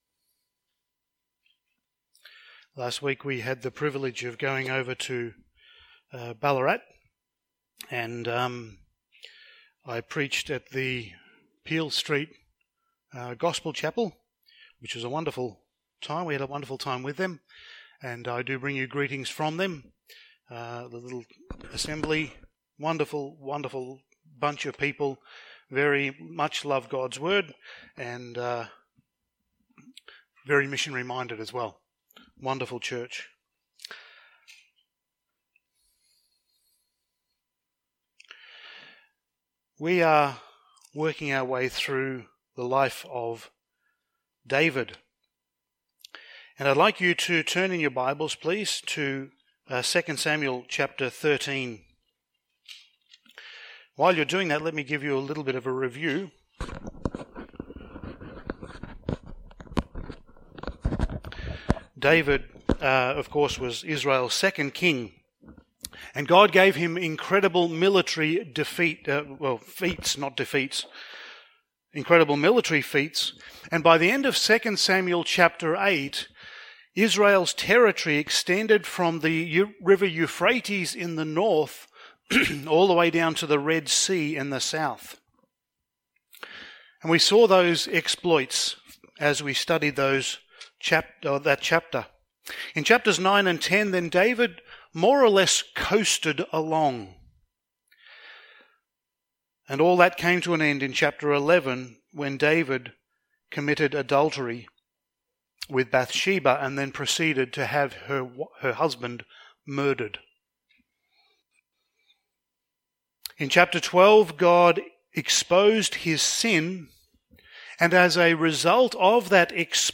Passage: 2 Samuel 13:1-39 Service Type: Sunday Morning